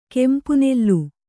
♪ kempu nellu